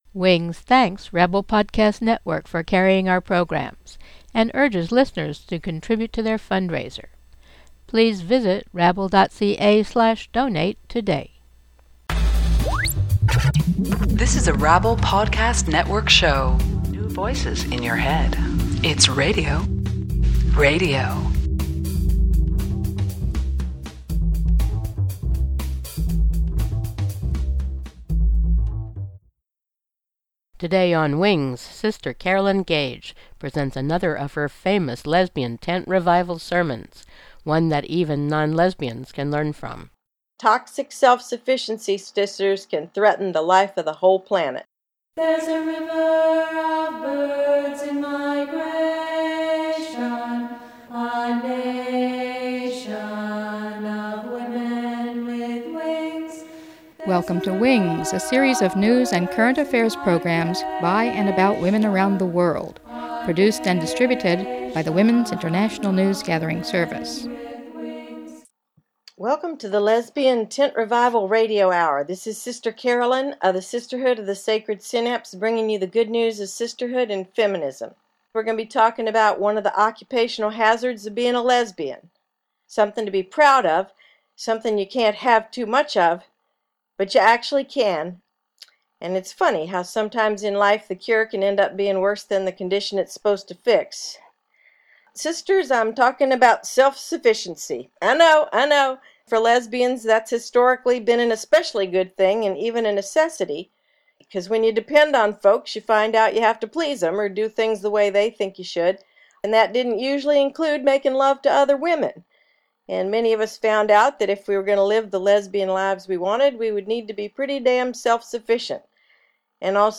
Toxic self-sufficiency: A Lesbian Tent Revival sermon